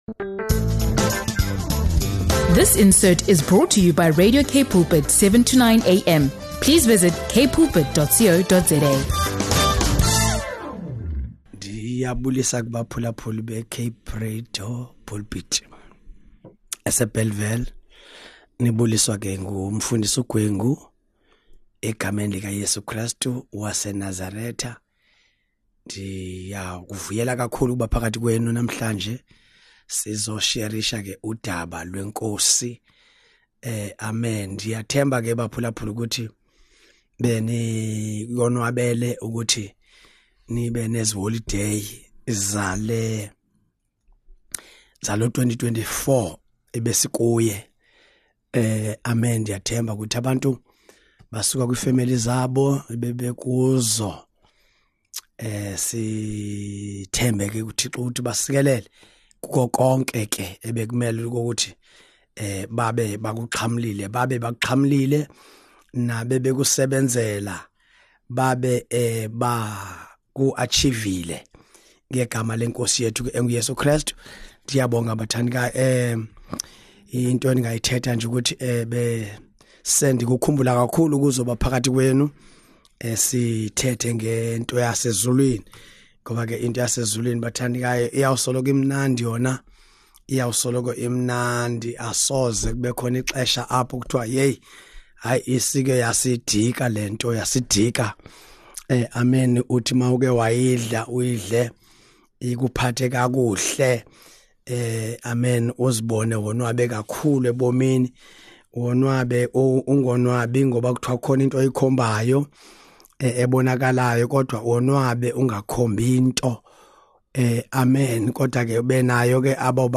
Join us as we explore the higher quality of perspective and examine the ways in which language connects different generations. Listen to this engaging conversation with thought-provoking questions related to desired and familiar places.